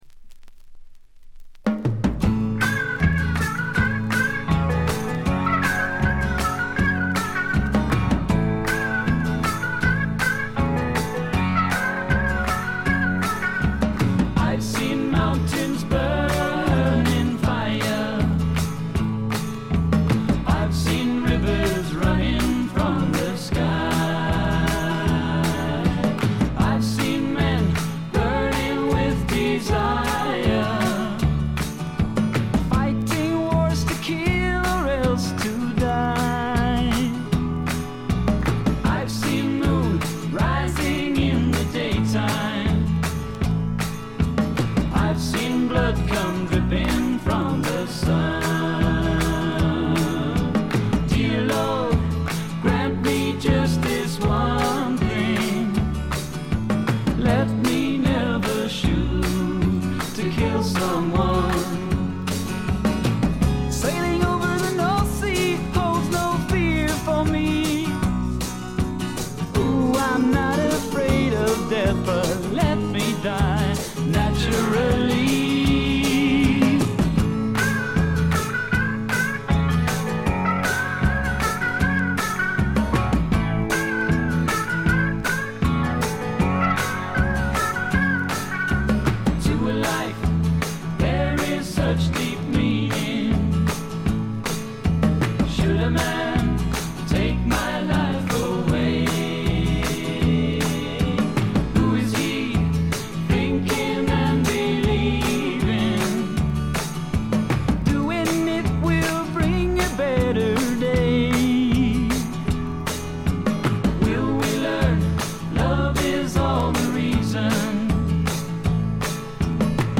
軽いチリプチ程度。
南アフリカのビートルズ風ポップ・ロック・バンド。
試聴曲は現品からの取り込み音源です。
Vocals, Acoustic Guitar
Vocals, Tambourine, Tabla